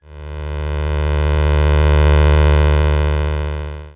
Hum41.wav